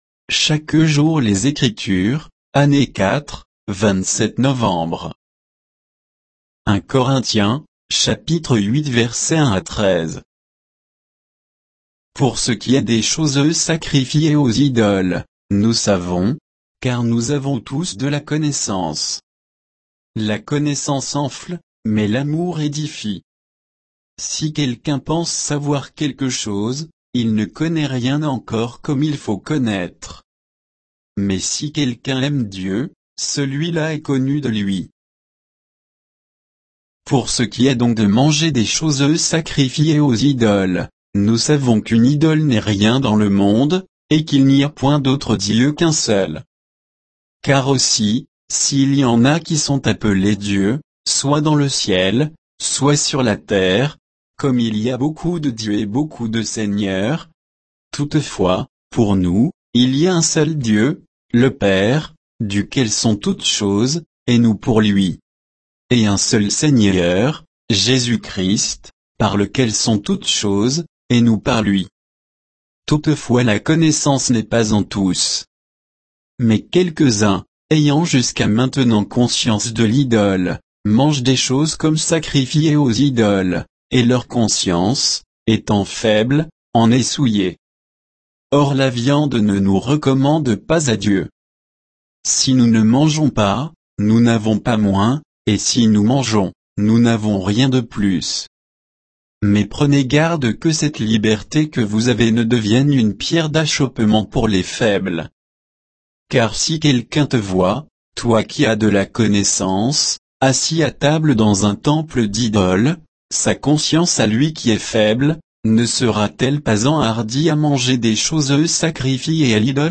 Méditation quoditienne de Chaque jour les Écritures sur 1 Corinthiens 8, 1 à 13